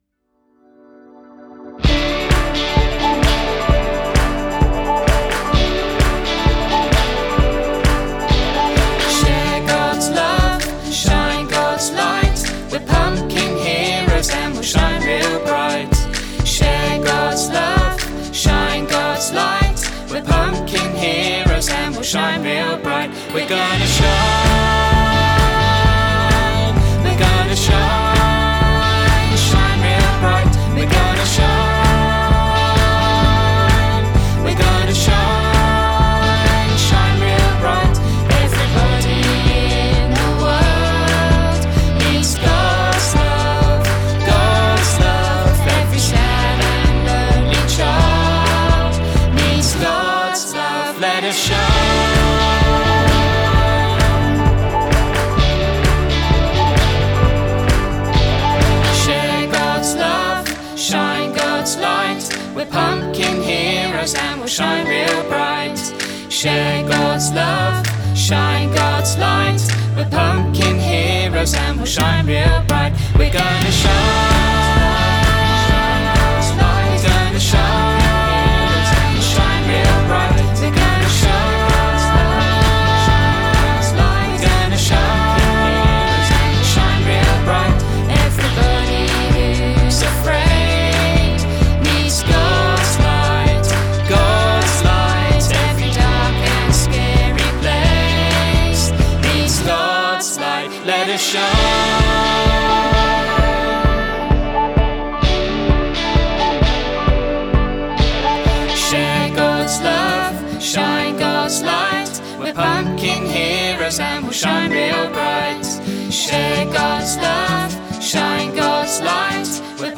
sing-along-song, and of course, the new cartoon film